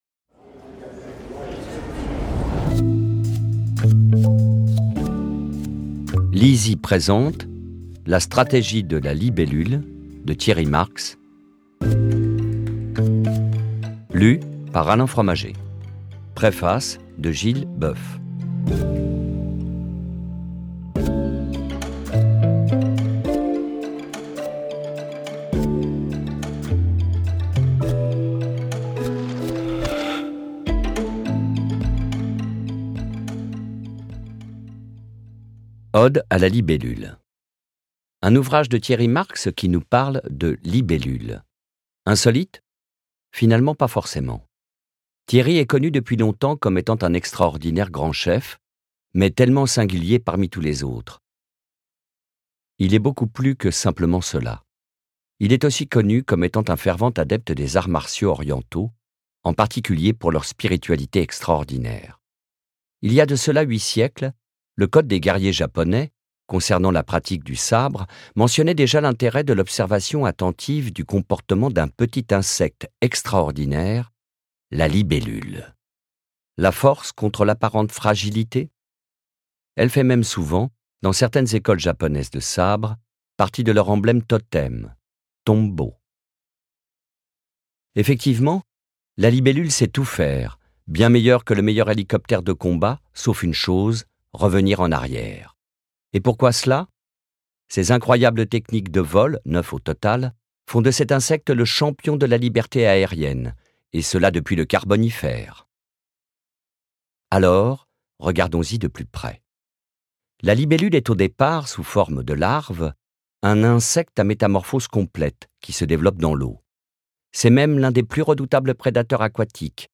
Extrait gratuit - La Stratégie de la libellule de Thierry Marx